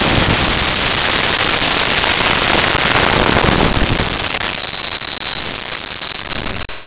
Cannon 1
Cannon_1.wav